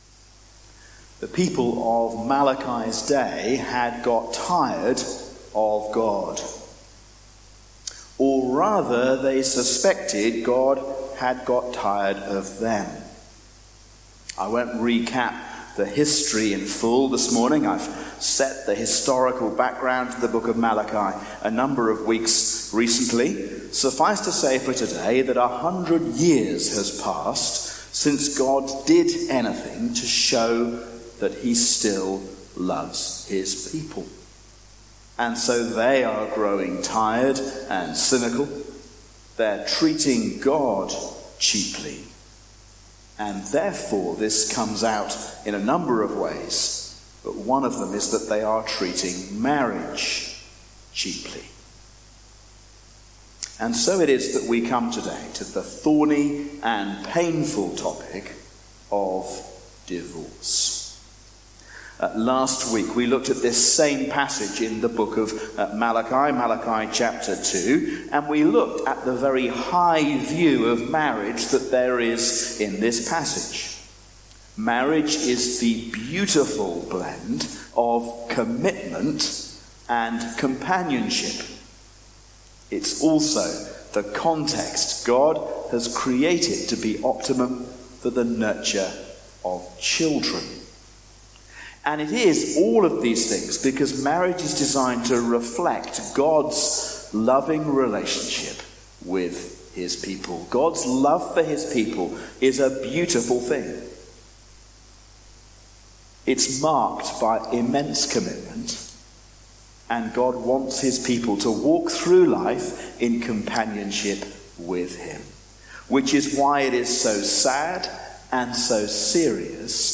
A sermon on Malachi 2:10-16